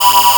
ihob/Assets/Extensions/RetroGamesSoundFX/Alert/Alert15.wav at master